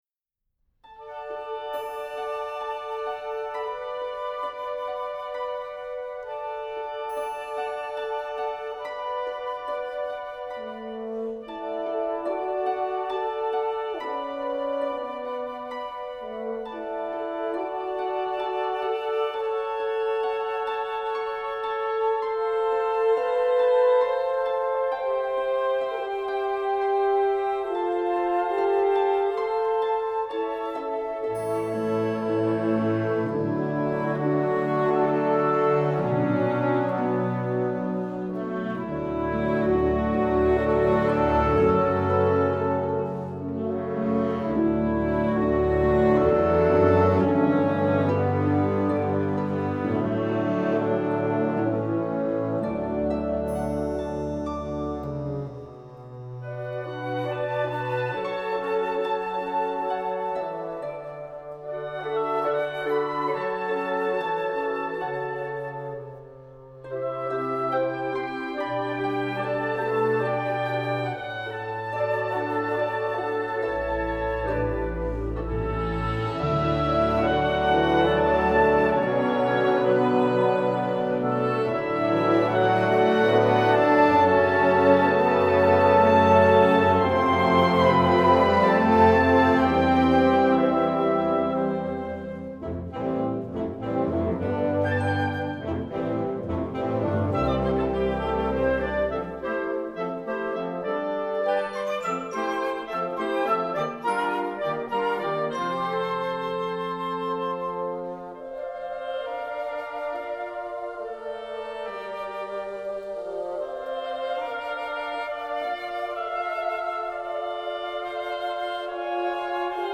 3:59 Minuten Besetzung: Blasorchester PDF